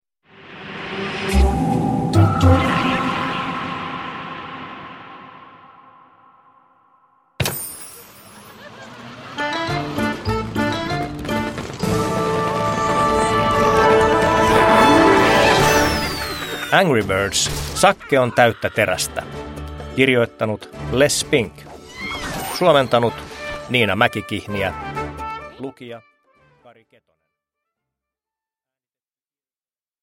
Angry Birds: Sakke on täyttä terästä – Ljudbok – Laddas ner